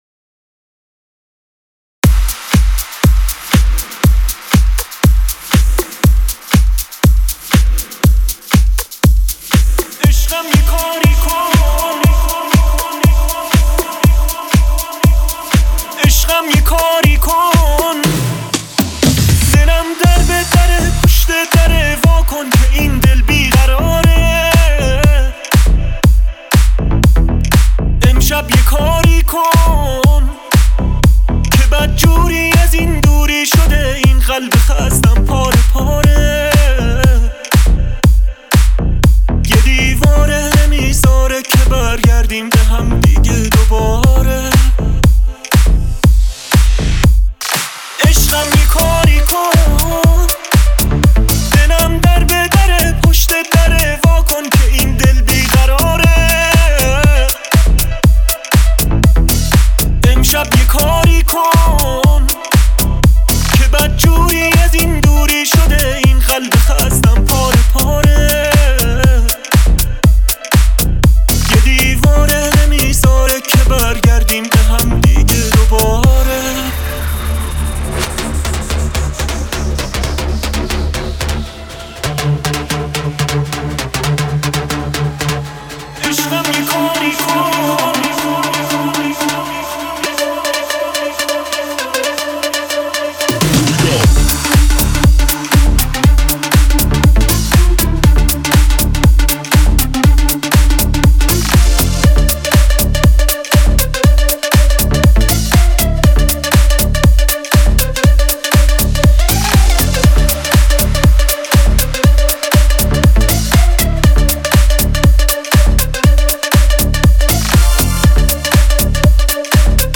Future House Mix